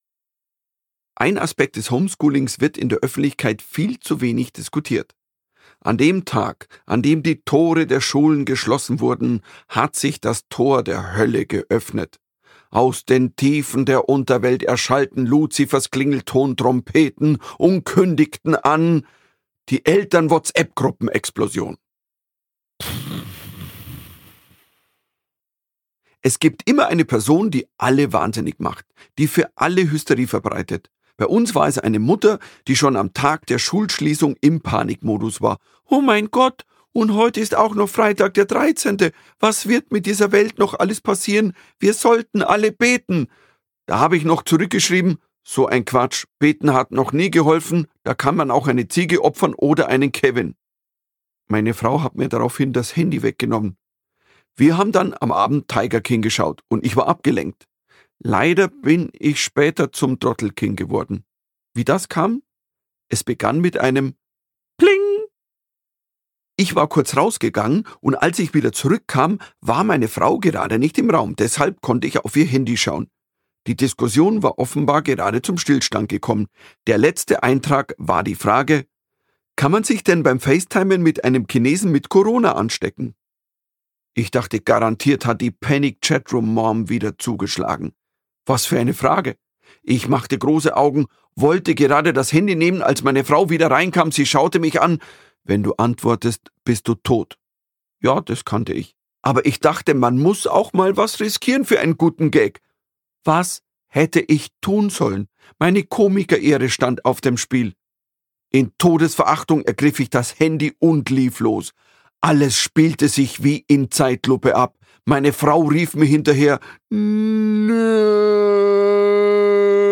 Ungekürzte Autorenlesung